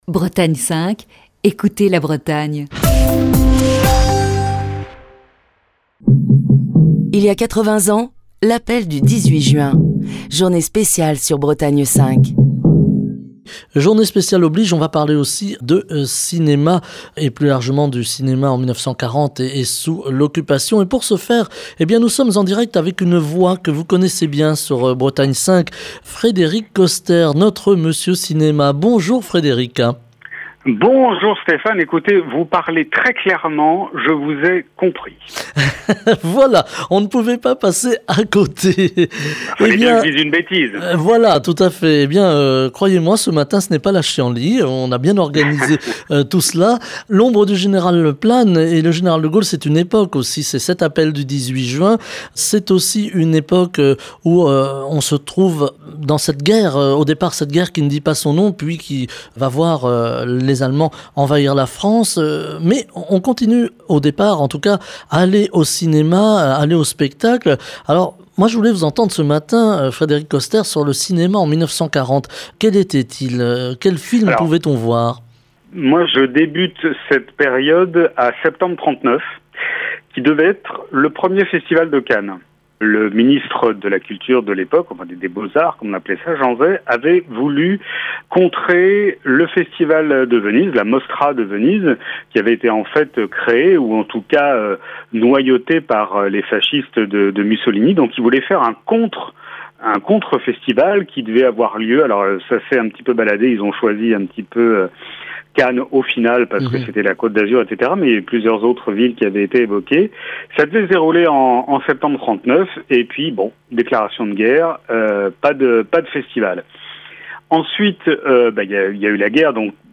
Chronique du 18 juin 2020. Journée spéciale 80 ans de l'appel du 18 juin.